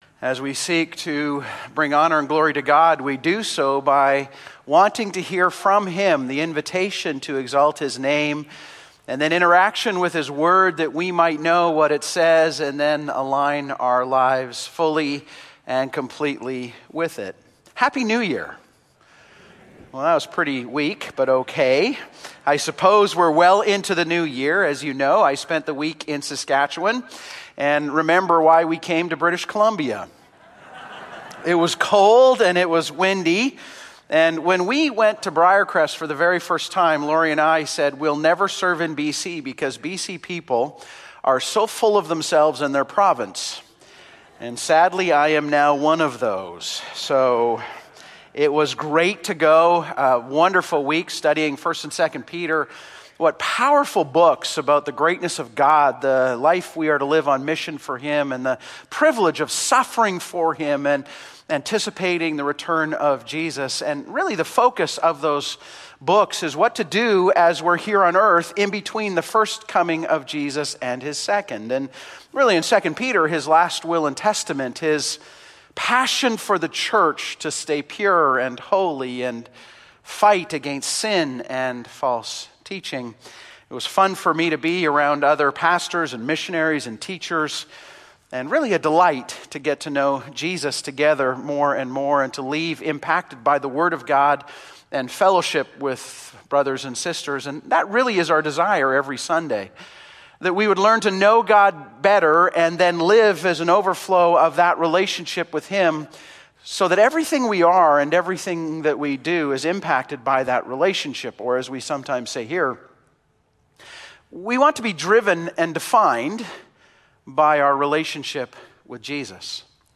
Audio Sermons from Cloverdale Baptist Church, located in Surrey, British Columbia.